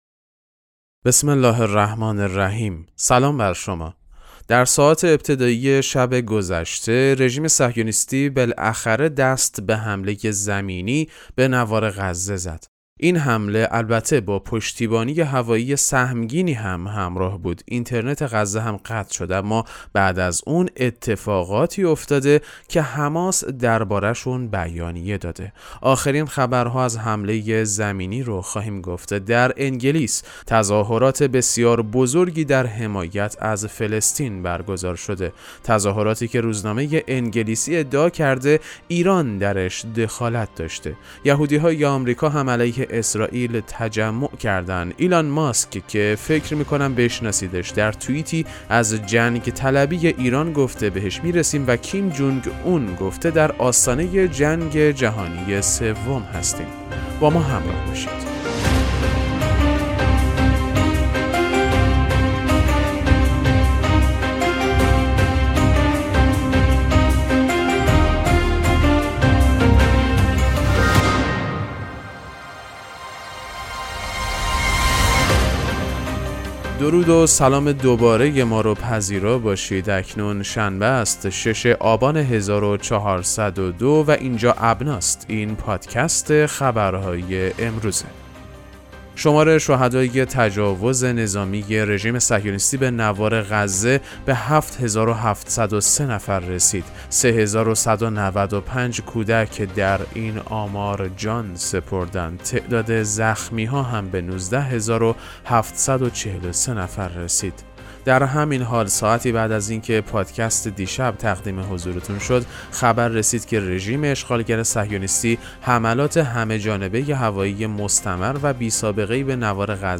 پادکست مهم‌ترین اخبار ابنا فارسی ــ 6 آبان 1402